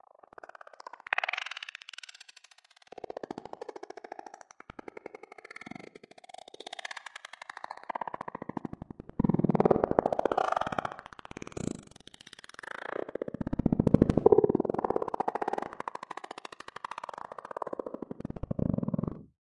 描述：噪音之旅
标签： 噪声 重复
声道立体声